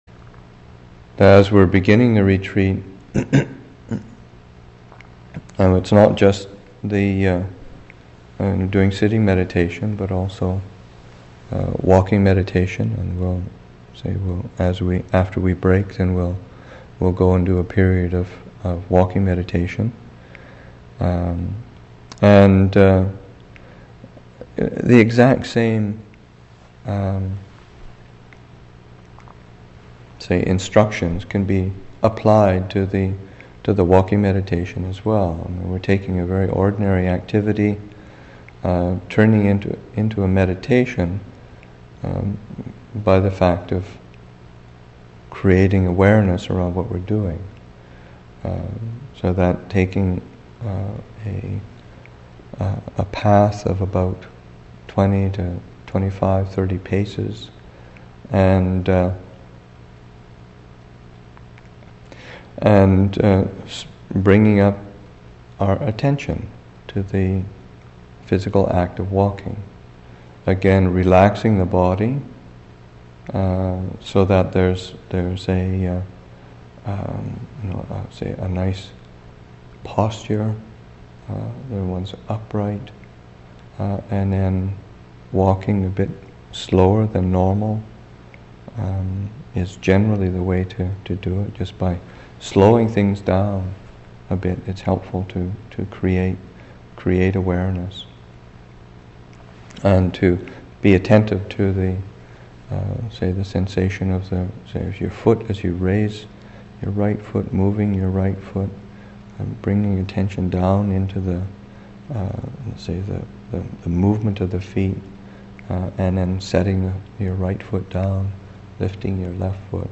Abhayagiri Buddhist Monastery in Redwood Valley, California